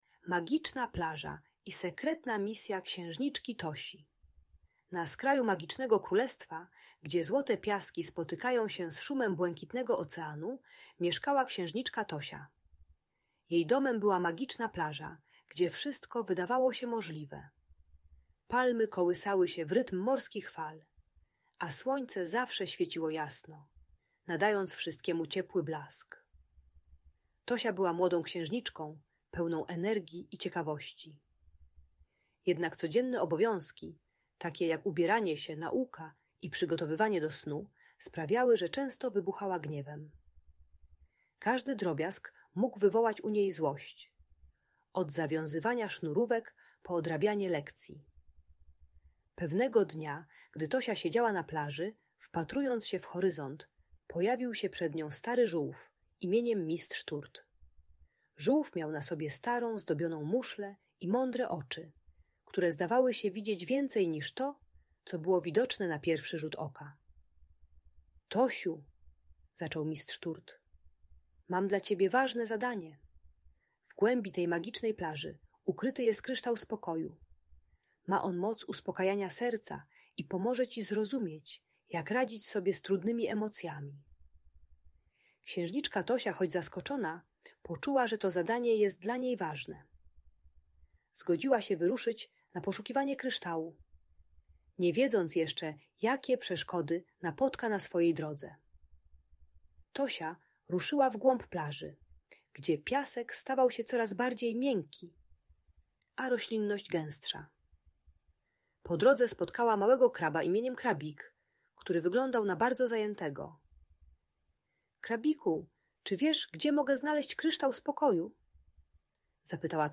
Sekretna Misja Księżniczki Tosi - - Bunt i wybuchy złości | Audiobajka